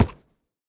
1.21.5 / assets / minecraft / sounds / mob / horse / wood6.ogg
wood6.ogg